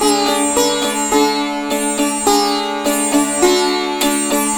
105-SITAR4-L.wav